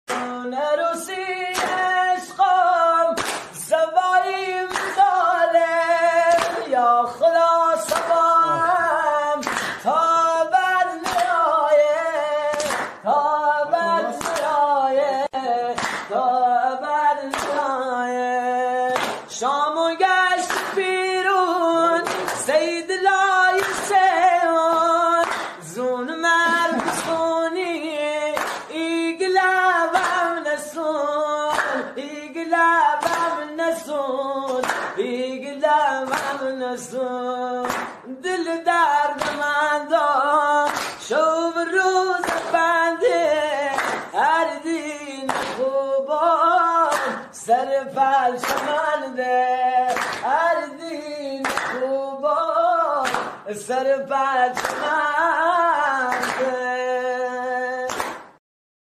آهنگ لری خرم آبادی معروف غمگین
آهنگ محلی غمگین و عاشقانه